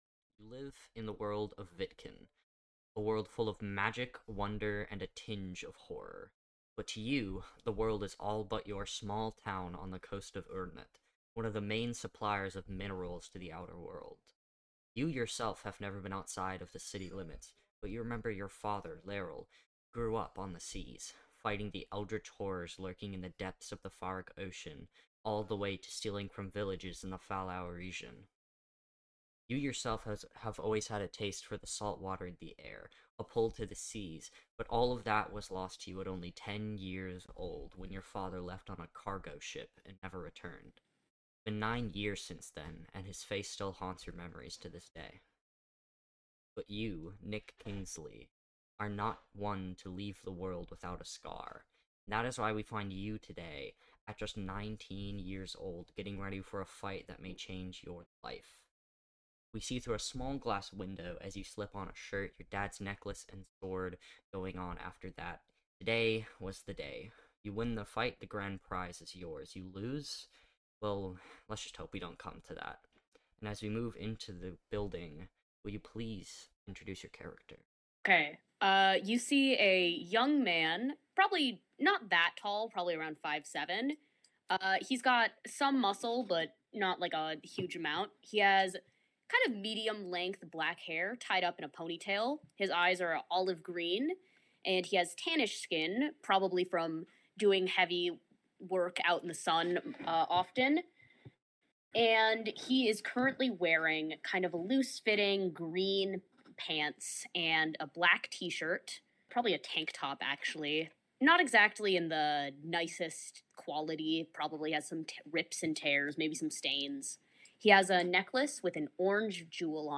Format: Audio RPG
Writing: Improvised Voices: Full cast
Soundscape: Voices only